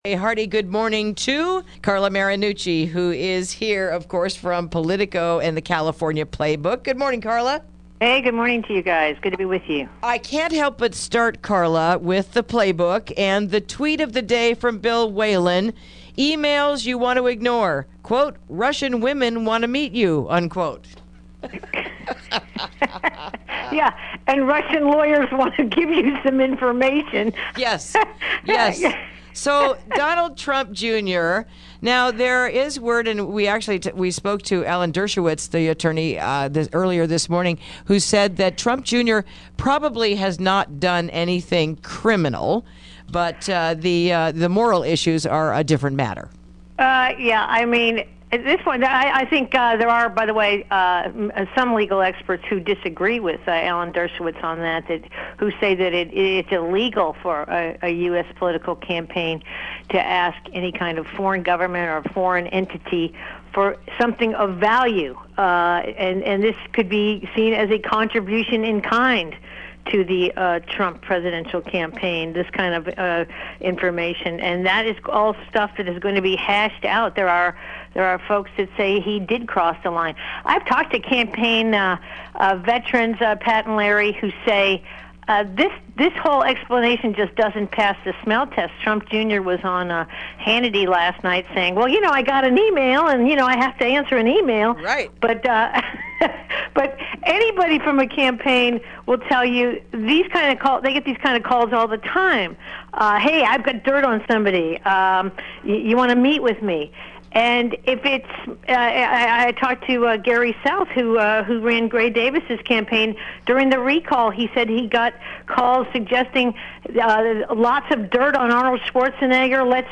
Interview: A Look Around the Golden State